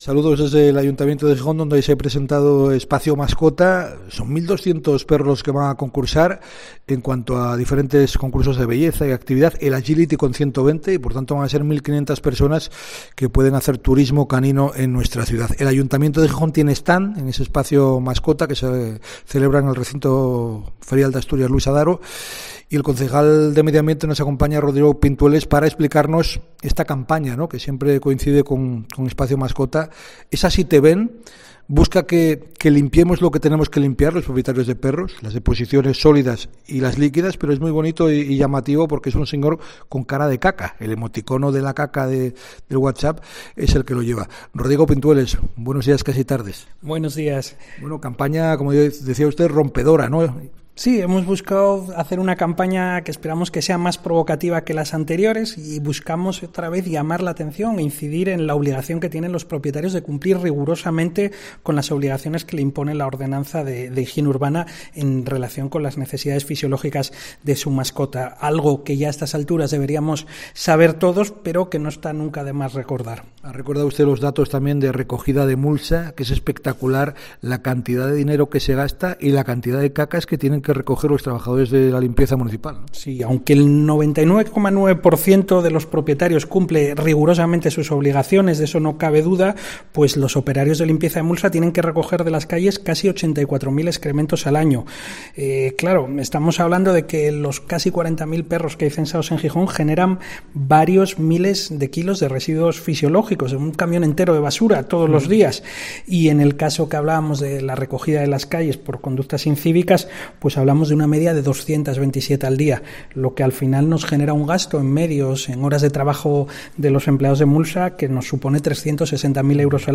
Entrevista a Rodrigo Pintueles (concejal de Medio Ambiente) sobre la campaña "Así te vemos"